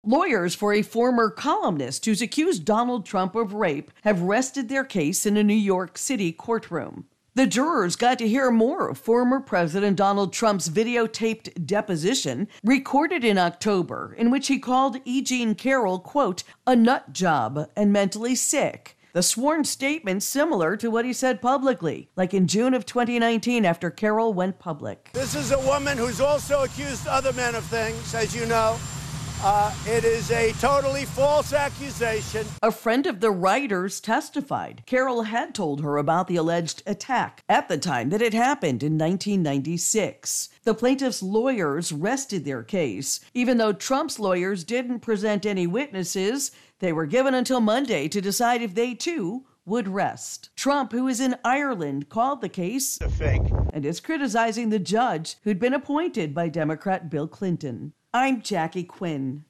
Trump calls accuser a 'nut job' in recording played for jury